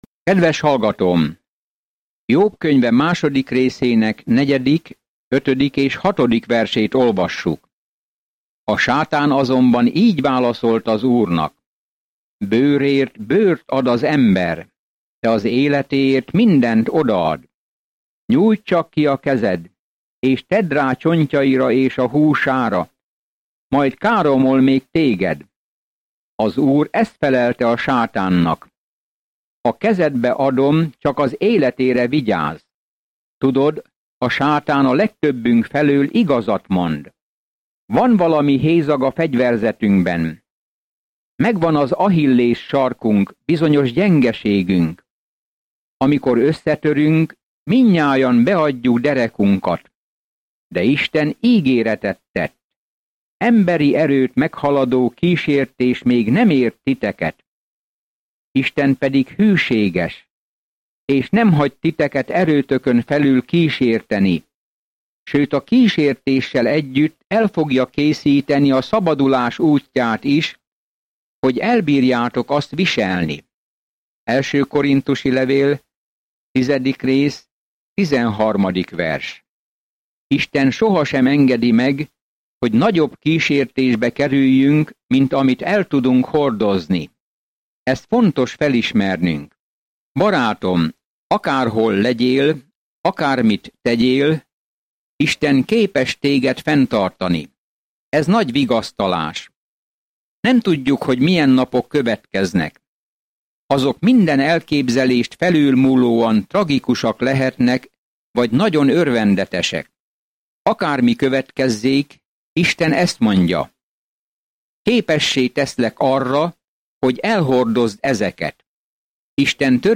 There is an audio attachment for this devotional.